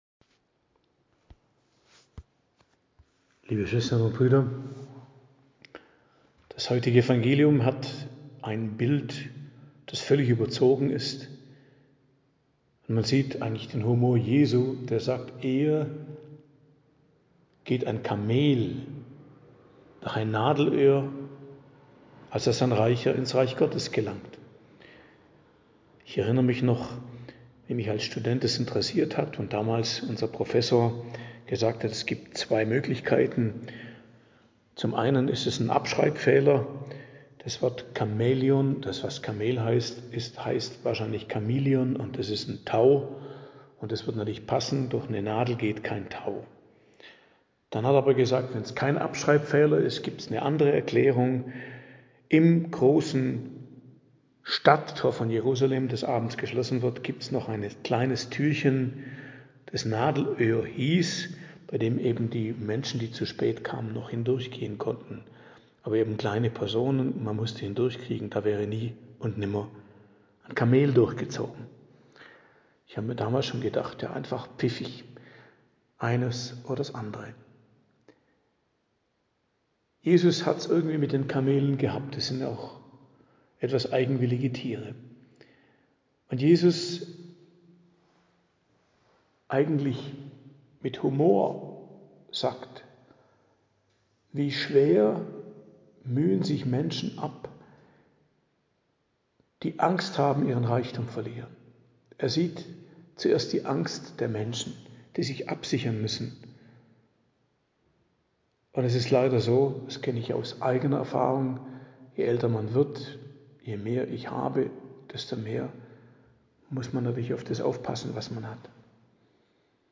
Predigt am Dienstag der 20. Woche i.J. 20.08.2024